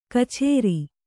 ♪ kchēri